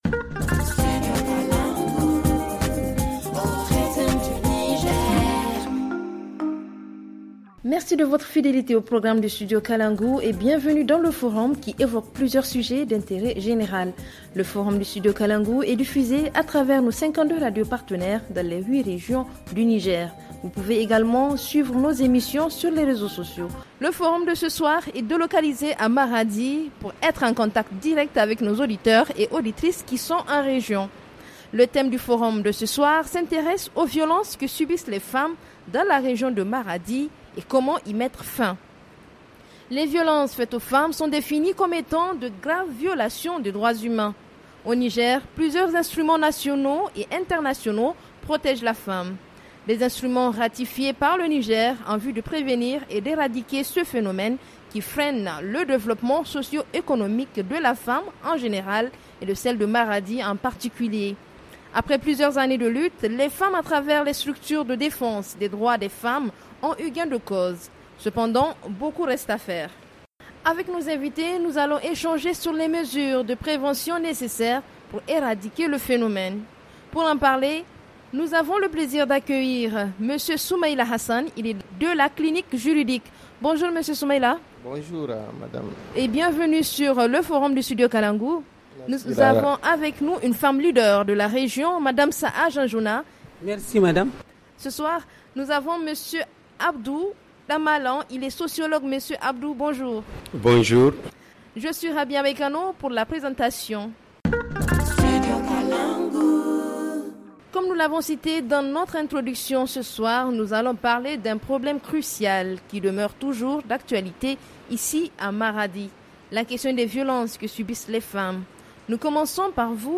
FR Le forum en français Télécharger le forum ici.